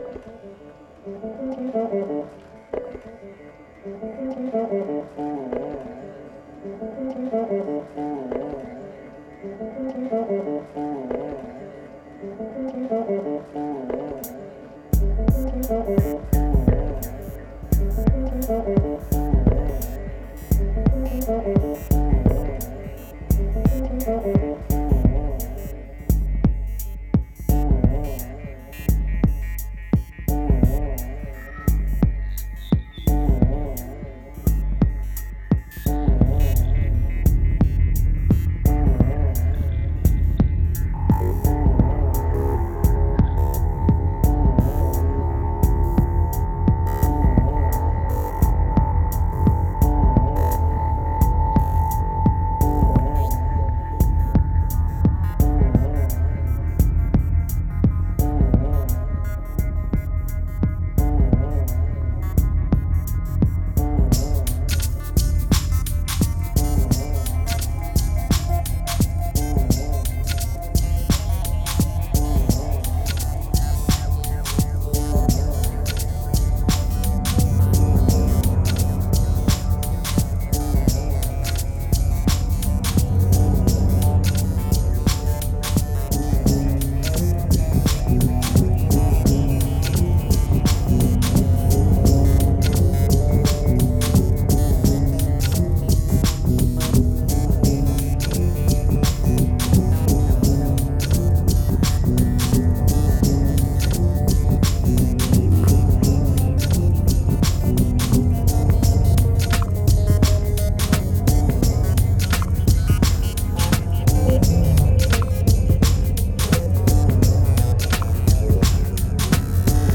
2118📈 - -5%🤔 - 86BPM🔊 - 2011-01-08📅 - -137🌟